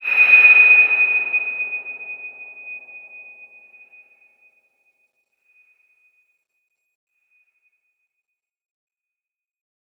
X_BasicBells-D#5-pp.wav